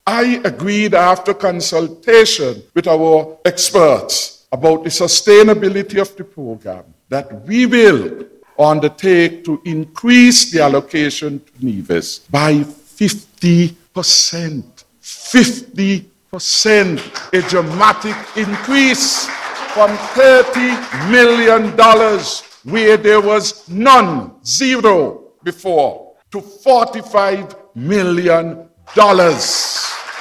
On Wednesday, March 16th, the People’s Labour Party (PLP) held a Press Conference for its upcoming Convention.
During that forum, Prime Minister Harris addressed the nation about Nevis’ Fair Share.